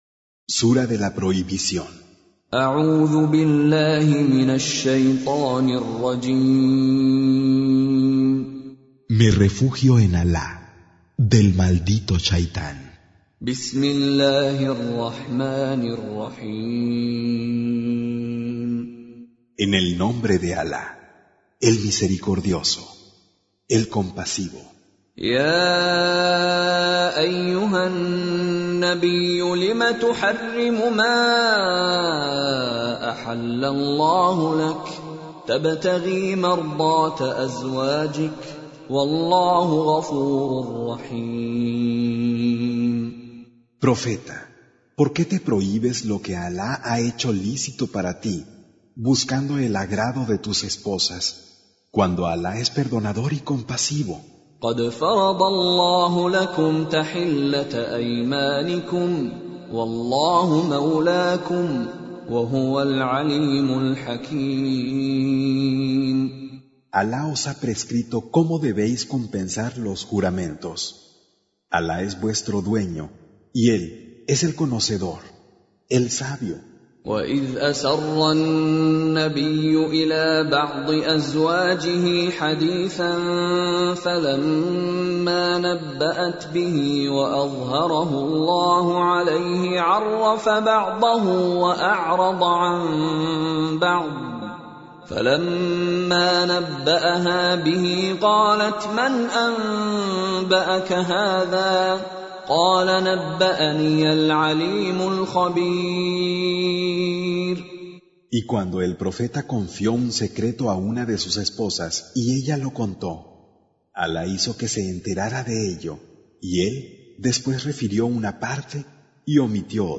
Surah Repeating تكرار السورة Download Surah حمّل السورة Reciting Mutarjamah Translation Audio for 66.